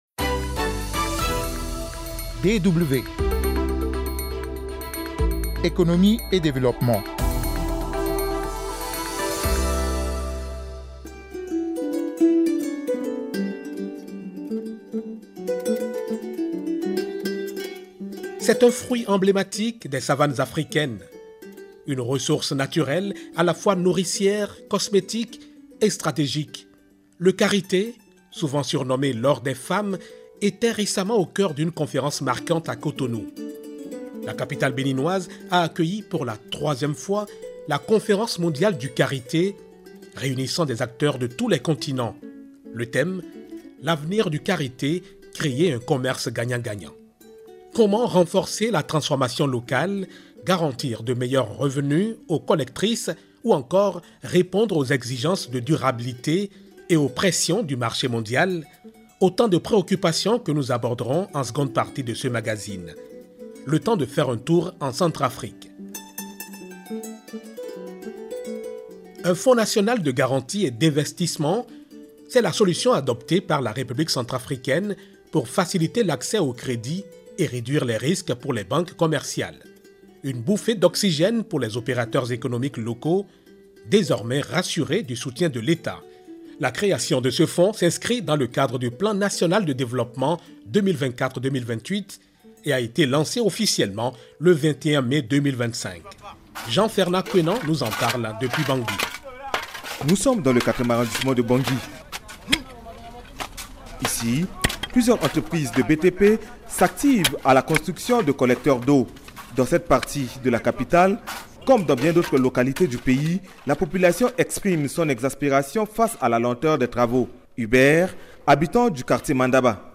Des analyses, des interviews et des reportages pour comprendre les évolutions actuelles, en Afrique et ailleurs.